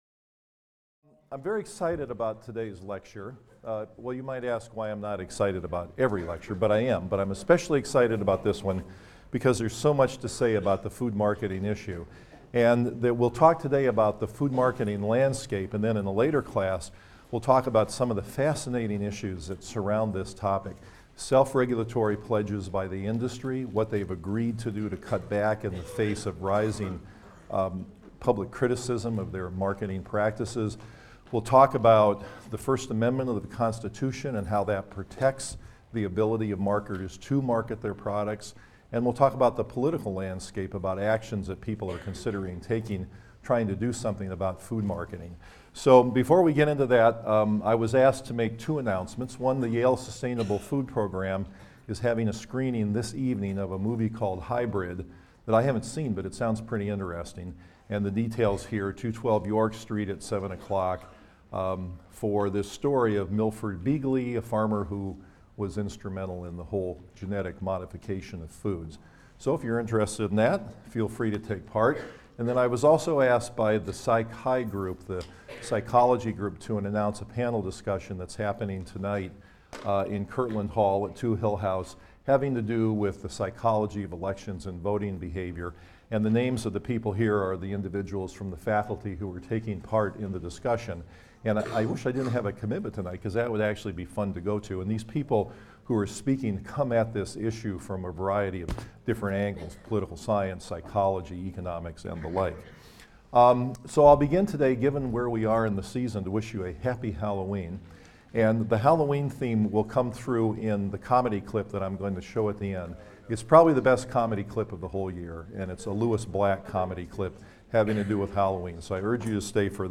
PSYC 123 - Lecture 16 - Everyone but Me: The Pervasive Reach and Powerful Influence of Food Marketing on Food Choices | Open Yale Courses